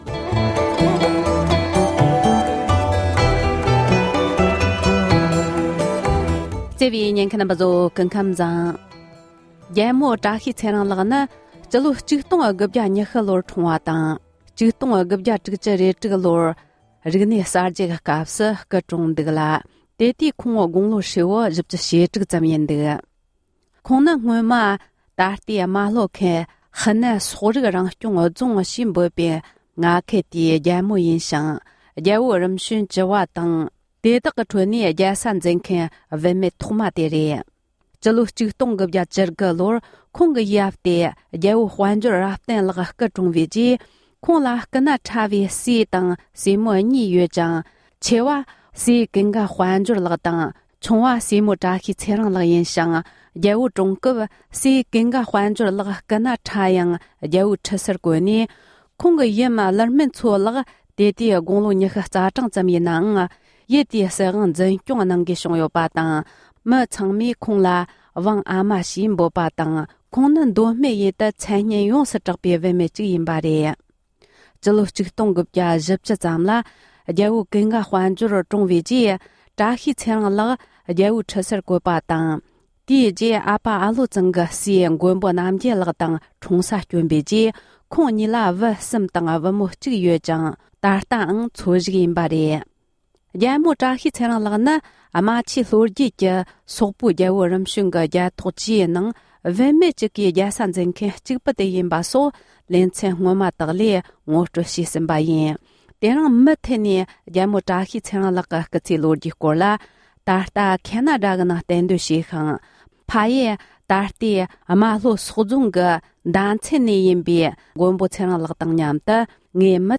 མདོ་སྨད་ཡུལ་གྱི་མཚན་གྲགས་ཆེ་བའི་རྨ་ལྷོ་སོག་རྫོང་གི་རྒྱལ་མོ་བཀྲ་ཤིས་ཚེ་རིང་གི་ལོ་རྒྱུས་སྐོར་གླེང་མོལ།